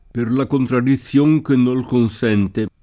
contraddizione [kontraddiZZL1ne] o contradizione [kontradiZZL1ne] s. f. — solo con -d- scempio (come il lat. contradictio [kqntrad&kZLo]) nei primi secoli: Per la contradizion che nol consente [